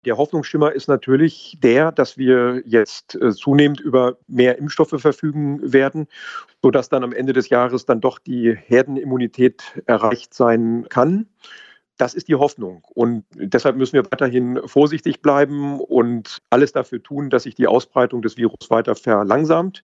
Landrat Dr. Martin Sommer